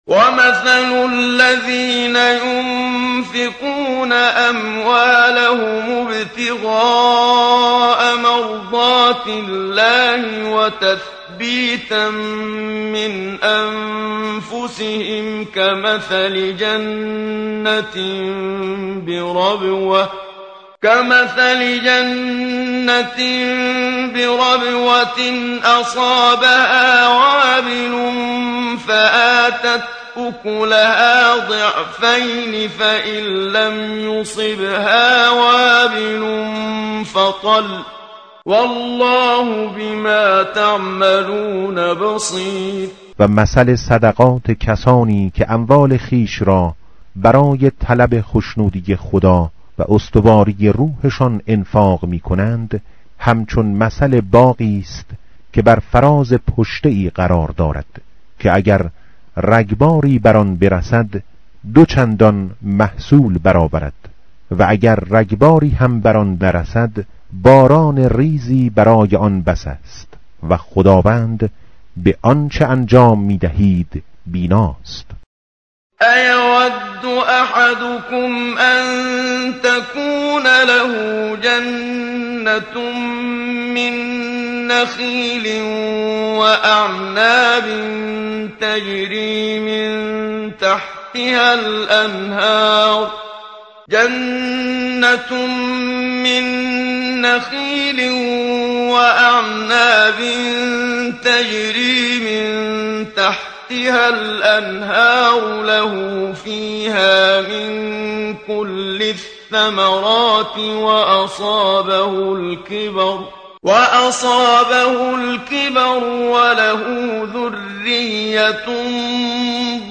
متن قرآن همراه باتلاوت قرآن و ترجمه
tartil_menshavi va tarjome_Page_045.mp3